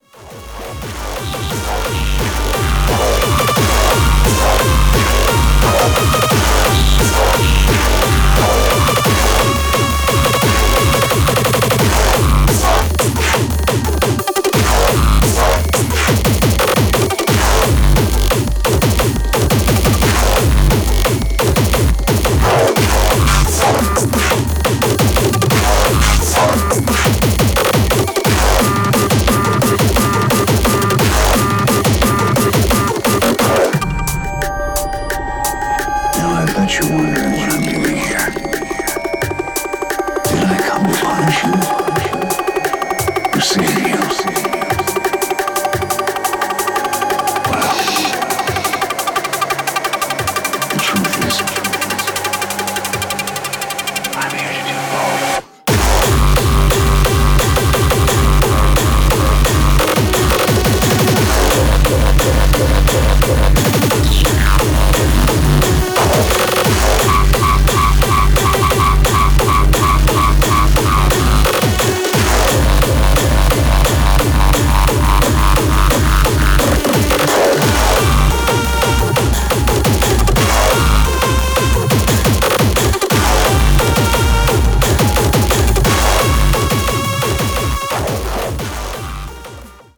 Styl: Techno, Minimal Vyd�no